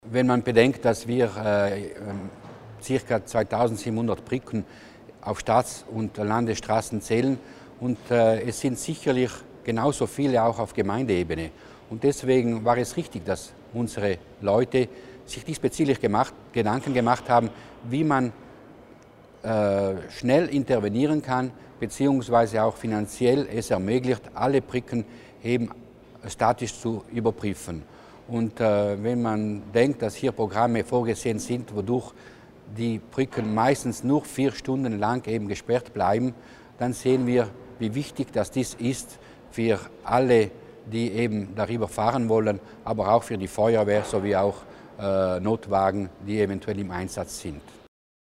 Landesrat Mussner zur Wichtigkeit der Instandhaltung der Brücken